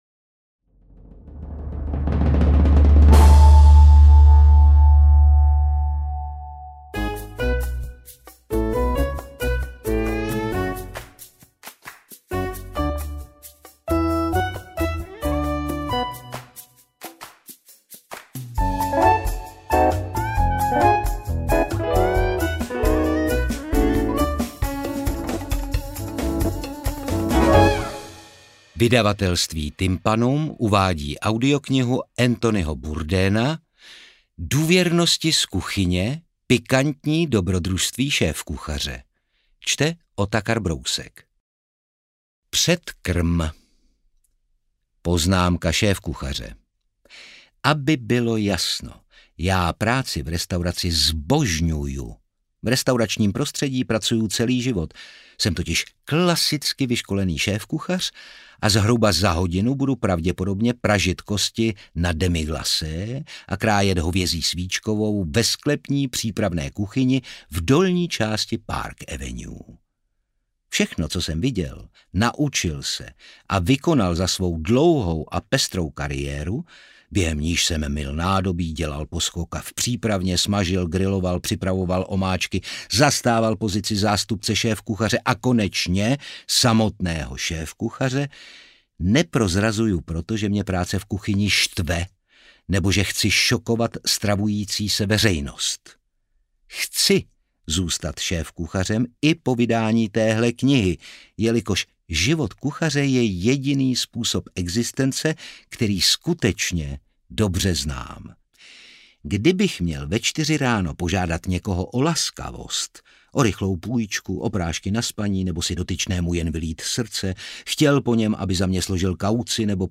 Interpret:  Otakar Brousek ml.